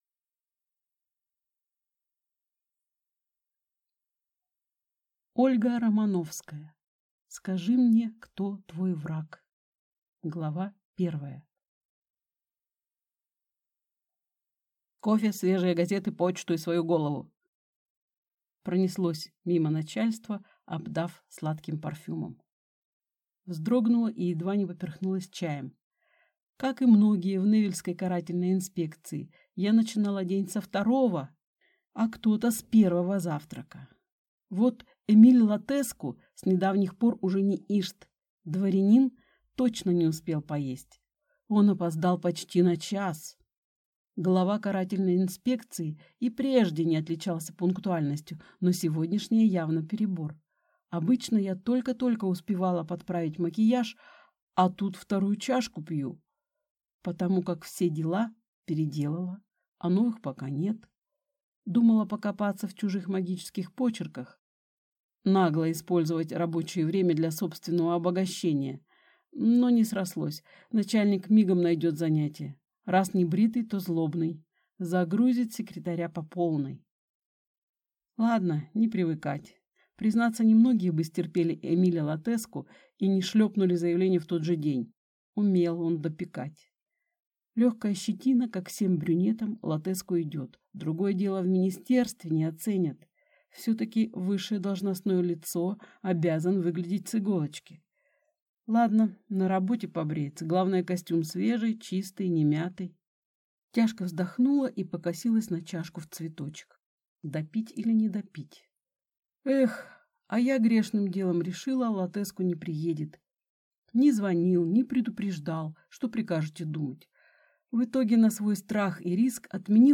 Аудиокнига Скажи мне, кто твой враг…